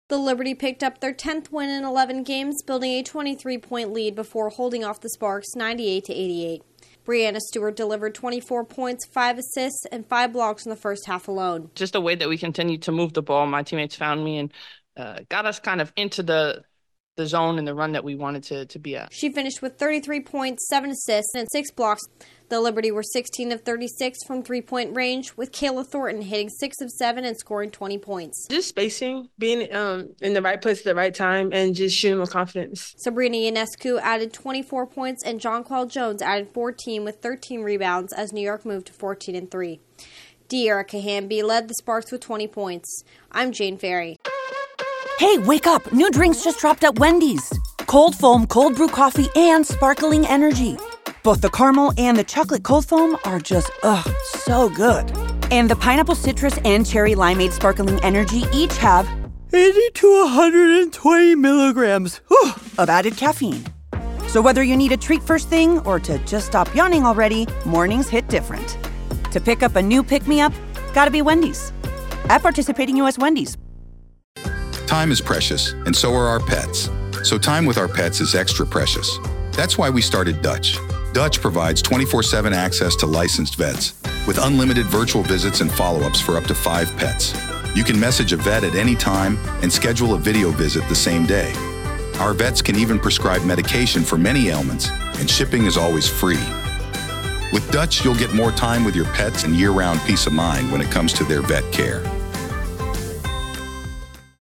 The Liberty beat the Sparks for the second time in three days. Correspondent